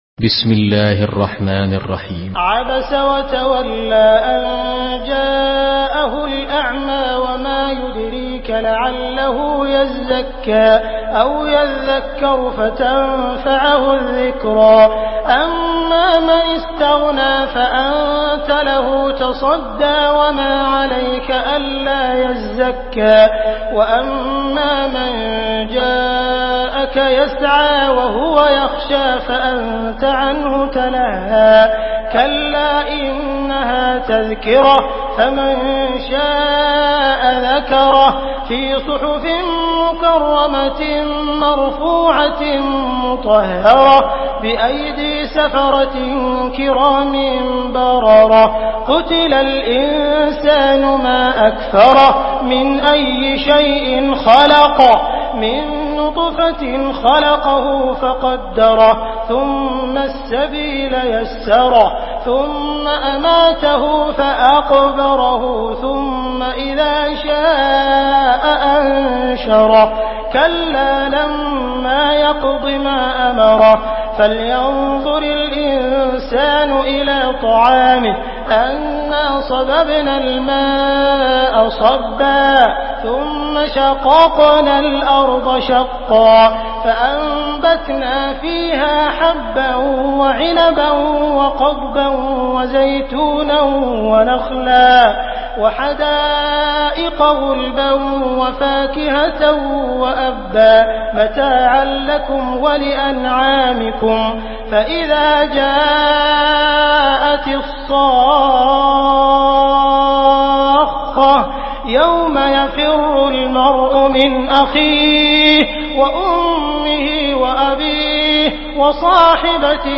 Surah عبس MP3 in the Voice of عبد الرحمن السديس in حفص Narration
Surah عبس MP3 by عبد الرحمن السديس in حفص عن عاصم narration.